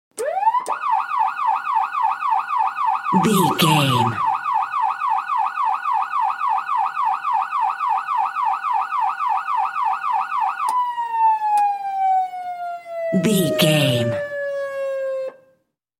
Ambulance Int Short Siren
Sound Effects
urban
chaotic
anxious
emergency